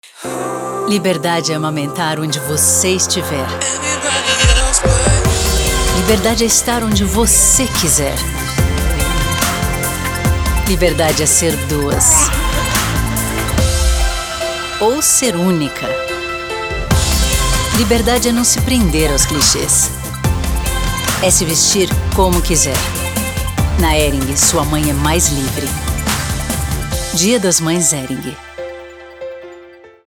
Imagerie radio
Je suis décrite comme une voix brésilienne chaleureuse et charmante.
Actuellement, mes fichiers audio sont enregistrés dans mon home studio à São Paulo, au Brésil, avec du matériel professionnel.
Mezzo-soprano